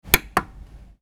Download Click Button sound effect for free.